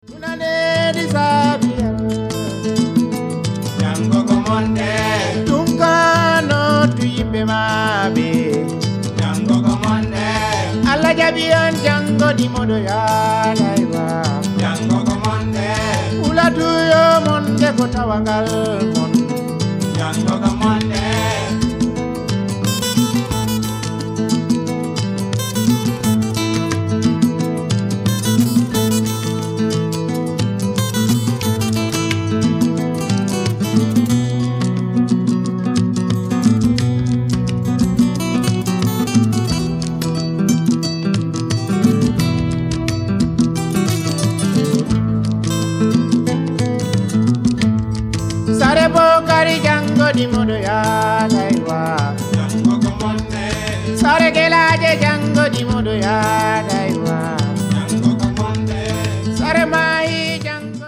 Casamance influenced music